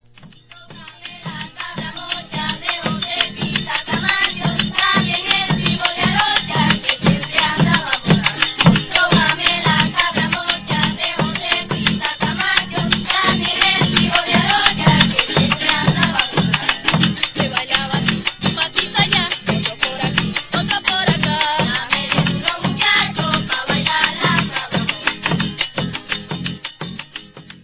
Tambor de frotación
Para ejecutarlo se coloca una vara vertical sobre la membrana, dicha vara, previamente encerada, se frota con las manos hasta transmitir la vibración que produce el sonido que caracteriza al instrumento.
Intérpretes: Grupo Gaitero infantil Las Fabulosas
Ensamble: Tambora, charrasca, cuatro, voces
Característica: Género musical propio de la época de Navidad, proviene especialmente del Estado Zulia.
Procedencia, año: Los Jovitos, Estado Zulia, Venezuela, 1979